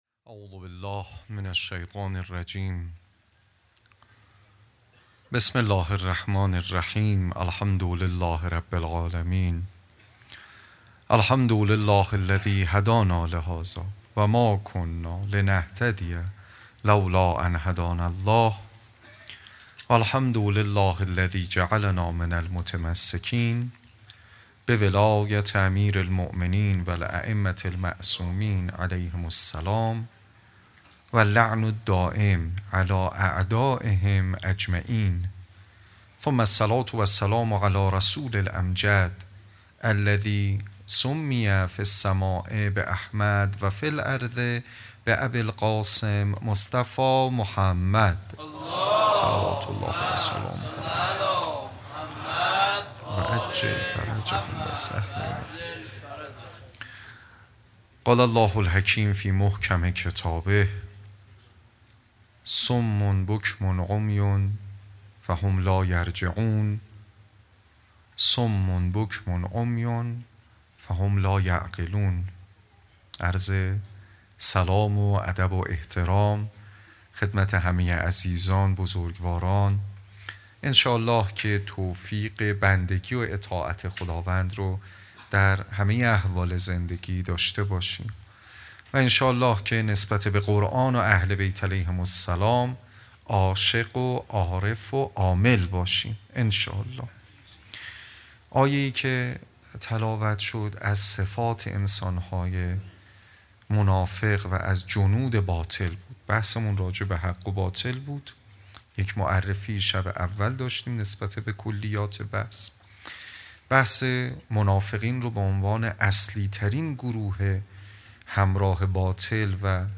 ♦مراسم عزاداری دهه دوم فاطمیه ۱۴۰۲